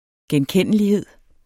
Udtale [ gεnˈkεnˀəliˌheðˀ ]